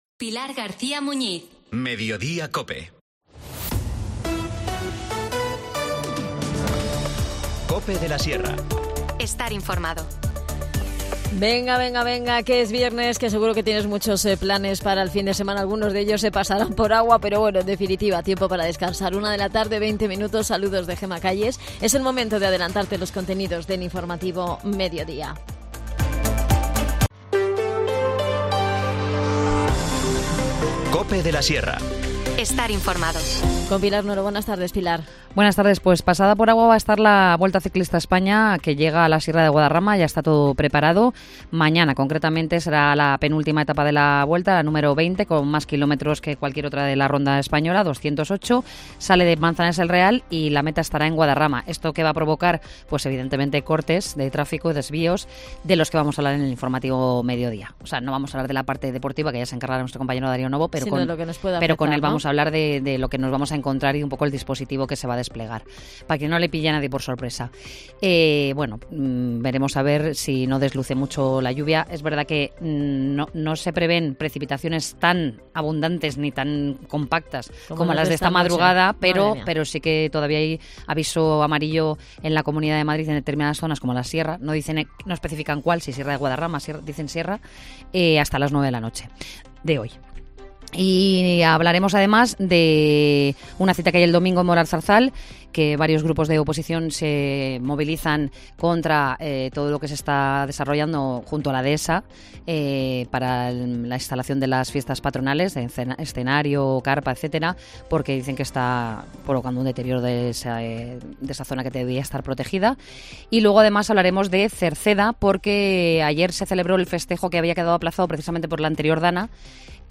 INFORMACIÓN LOCAL
Hoyo de Manzanares ha sido escenario de películas, cuenta con un yacimiento arqueológico, un entorno natural lleno de encanto, una amplia oferta gastronómica y muchas cosas por descubrir. Te las contamos con Rocío Cabrera , concejal de Turismo de la localidad.